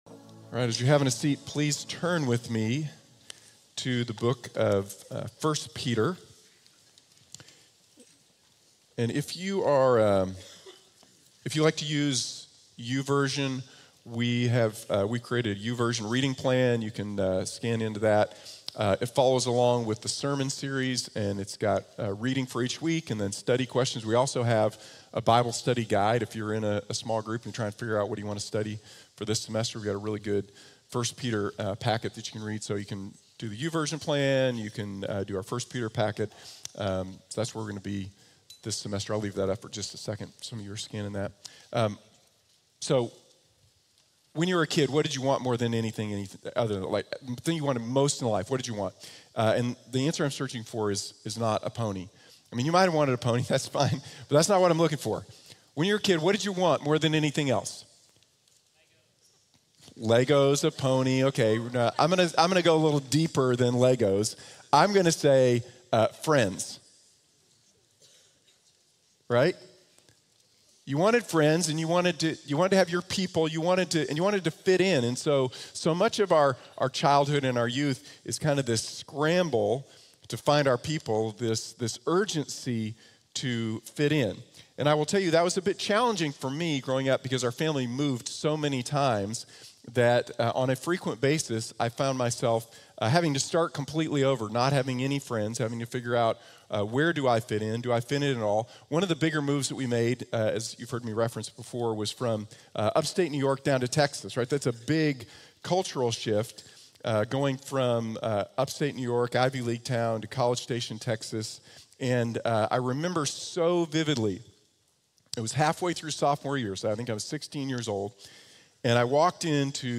Our Hope is Secure | Sermon | Grace Bible Church